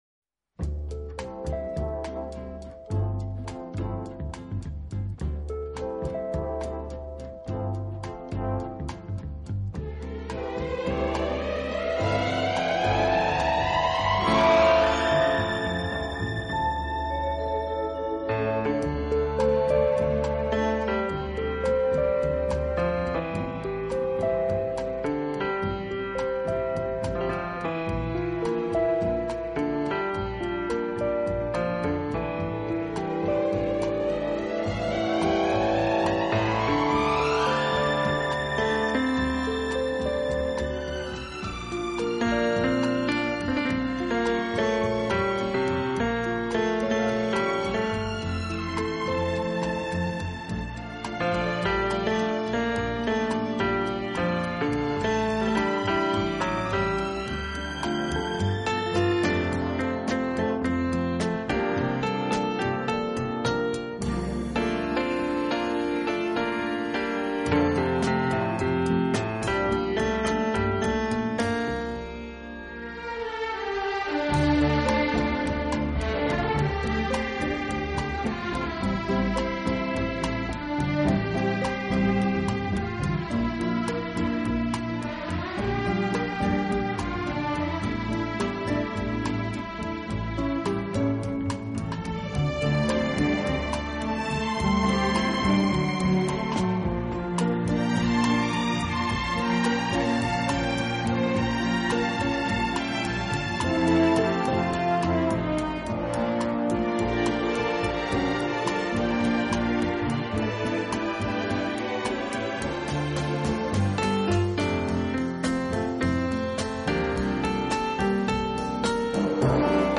勾勒出华丽而精致的背景，虽然间或也会采用打击乐。